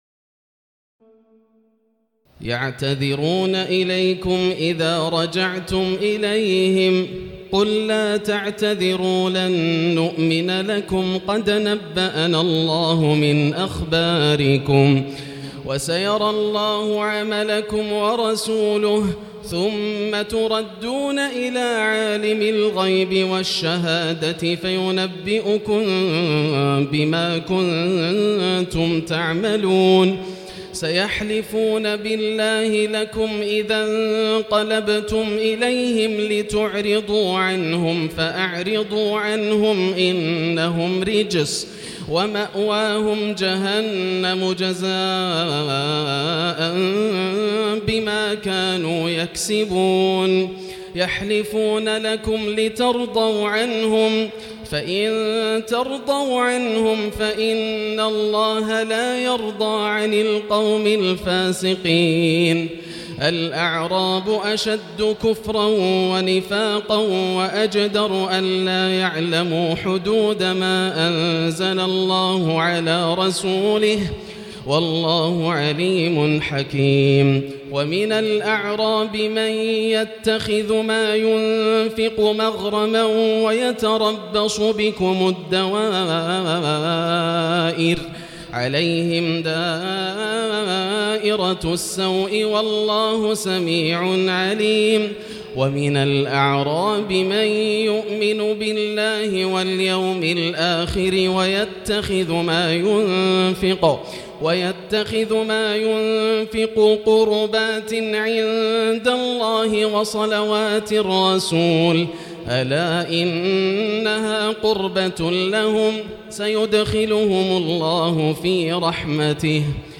تراويح الليلة العاشرة رمضان 1439هـ من سورتي التوبة (94-129) و يونس (1-25) Taraweeh 10 st night Ramadan 1439H from Surah At-Tawba and Yunus > تراويح الحرم المكي عام 1439 🕋 > التراويح - تلاوات الحرمين